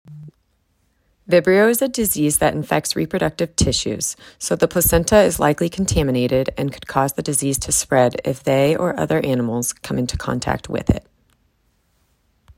Normal_v2.mp3